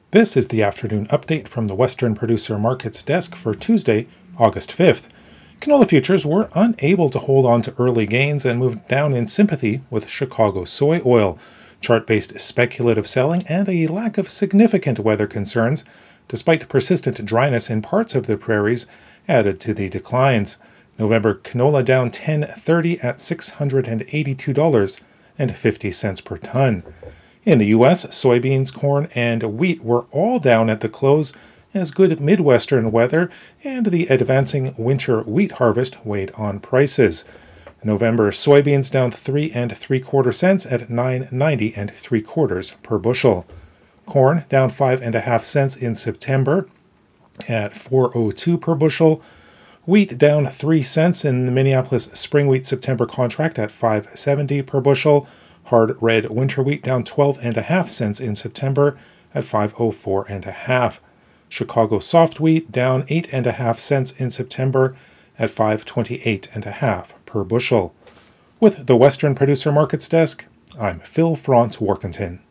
MarketsFarm's radio show is delivered twice per day - at noon and at the close of markets - and contains the latest information on the price of canola, wheat, soybeans, corn and specialty crops.